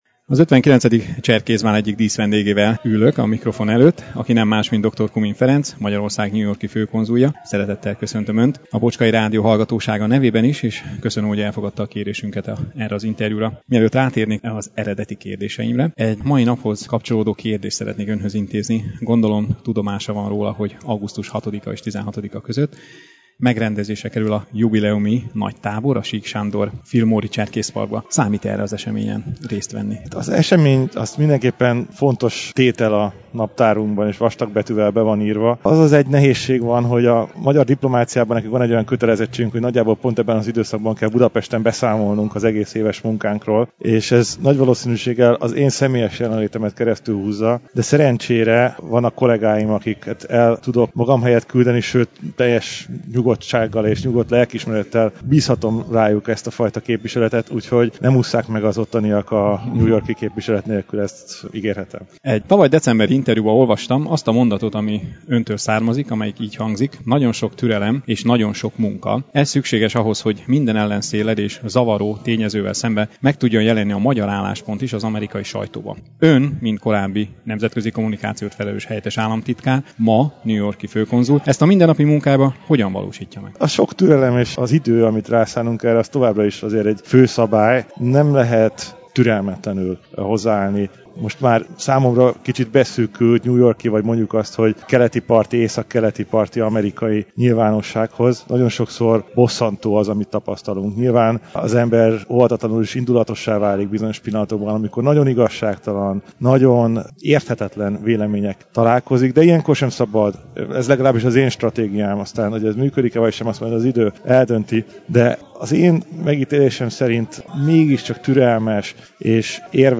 Beszámoló az 59. Cserkészbálról és interjú dr. Kumin Ferenccel a bál díszvédnökével – Bocskai Rádió